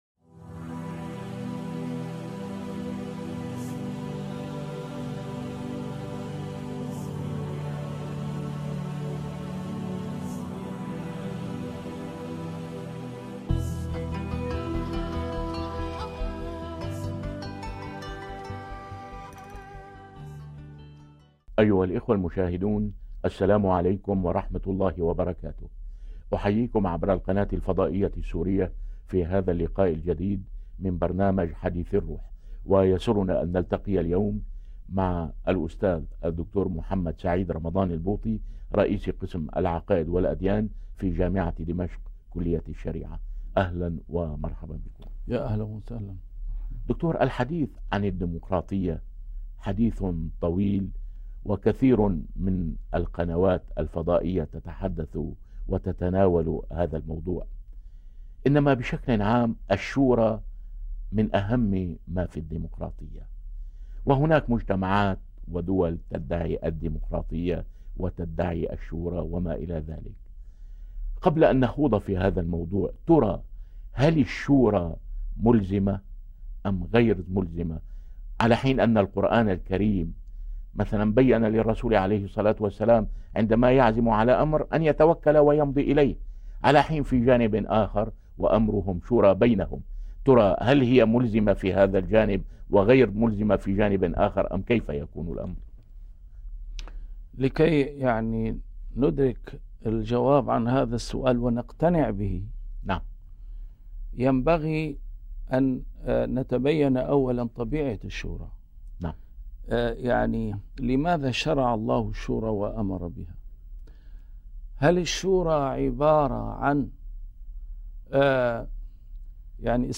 A MARTYR SCHOLAR: IMAM MUHAMMAD SAEED RAMADAN AL-BOUTI - الدروس العلمية - محاضرات متفرقة في مناسبات مختلفة - الديمقراطية في ميزان الإسلام